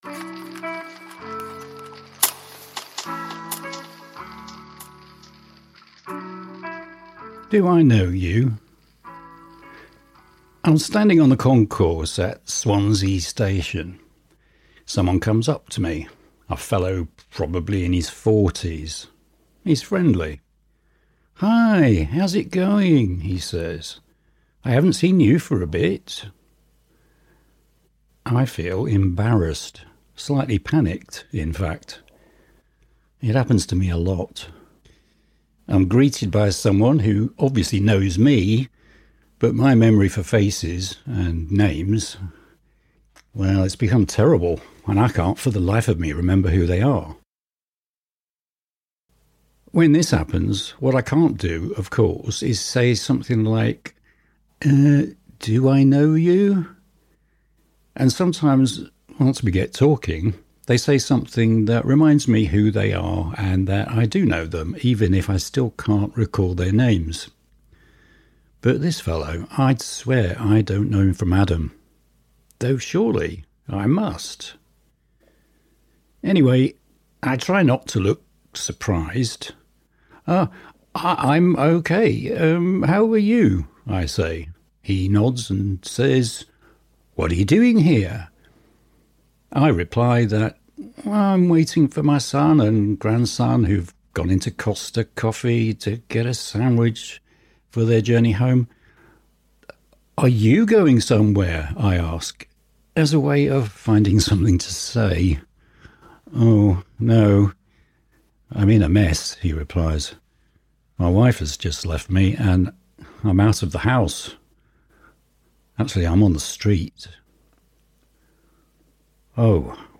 Featuring music: 'Pushing P (Instrumental)' by Tiga Maine x Deejay Boe.